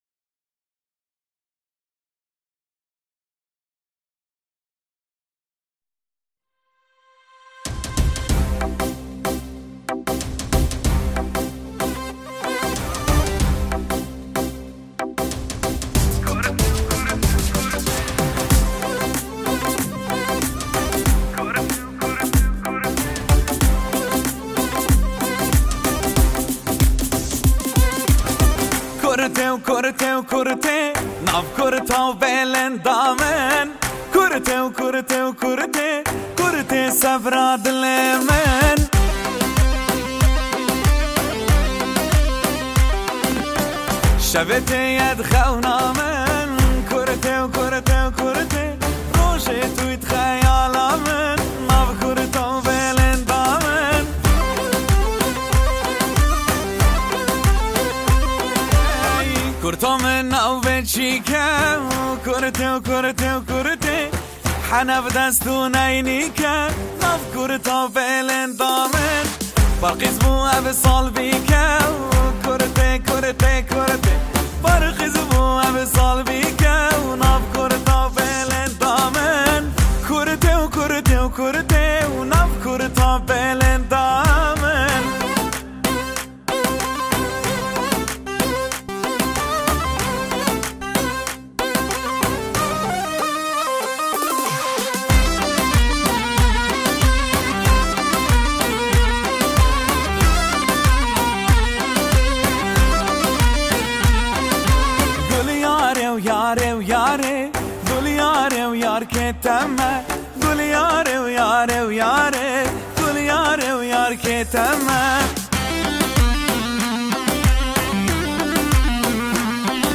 دانلود اهنگ کردی